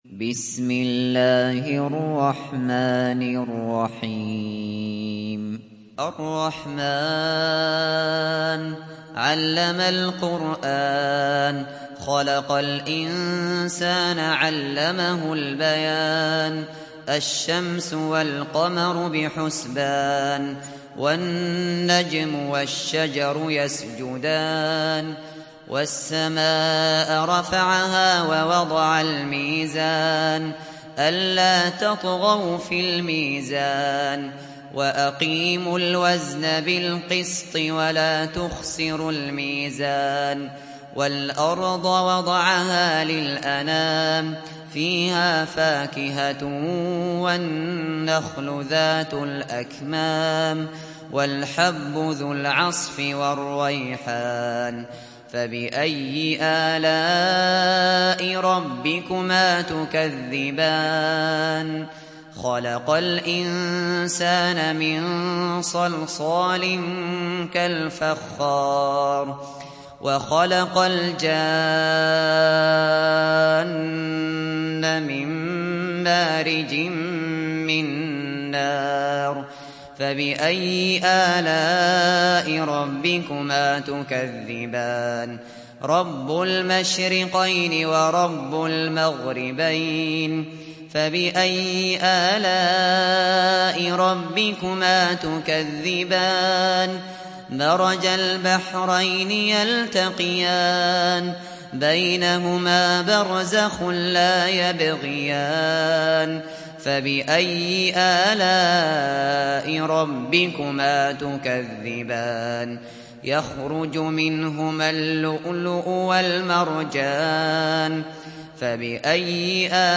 دانلود سوره الرحمن mp3 أبو بكر الشاطري روایت حفص از عاصم, قرآن را دانلود کنید و گوش کن mp3 ، لینک مستقیم کامل